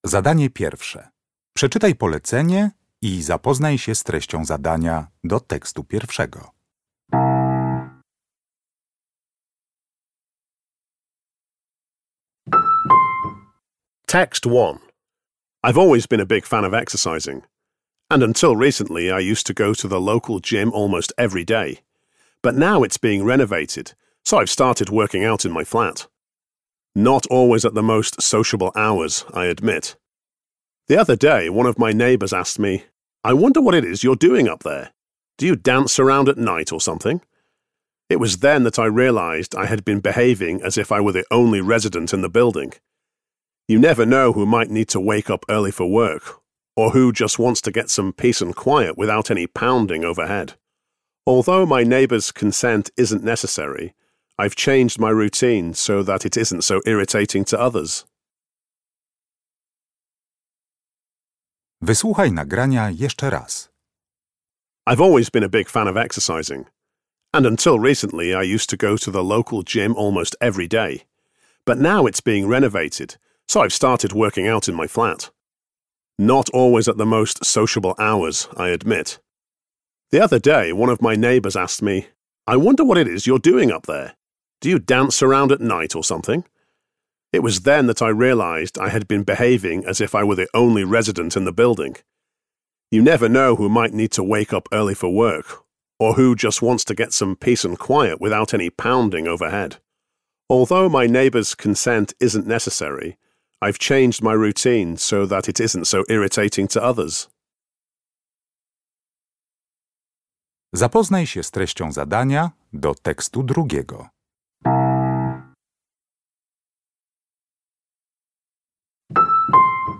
Uruchamiając odtwarzacz z oryginalnym nagraniem CKE usłyszysz dwukrotnie trzy teksty.